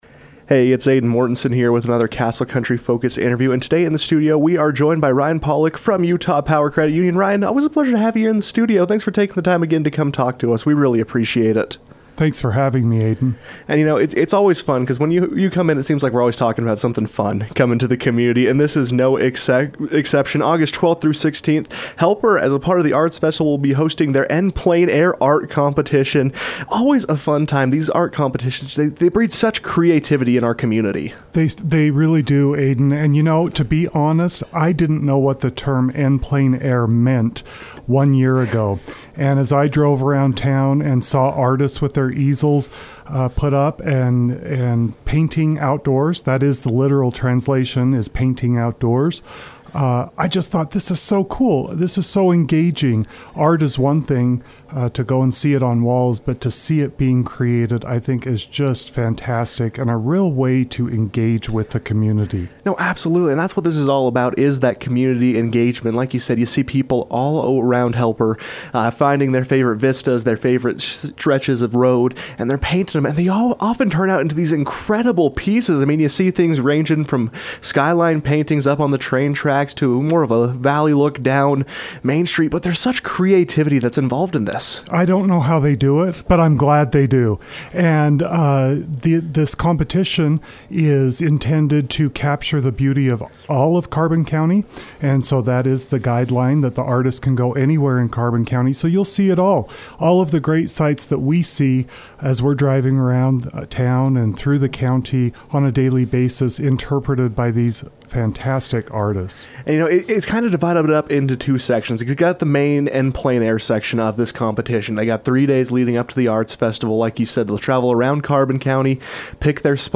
KOAL News As the 2025 rendition of the Helper Arts Festival approaches